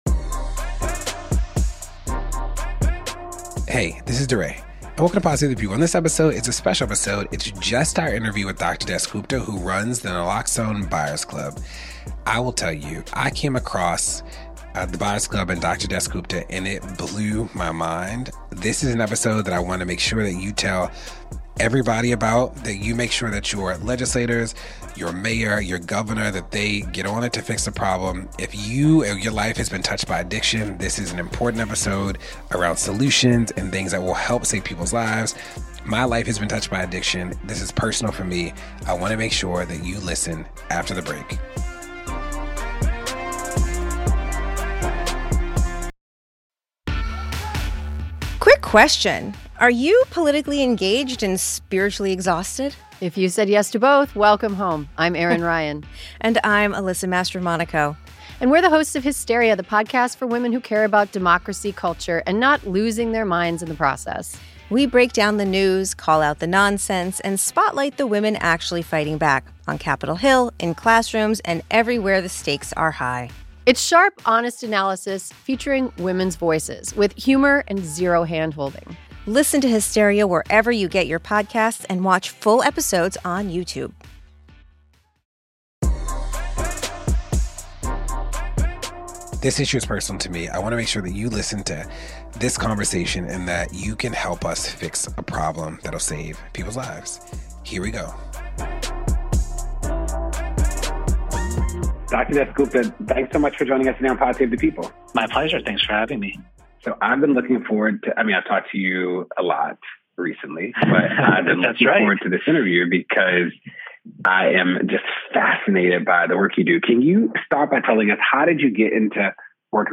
DeRay interviews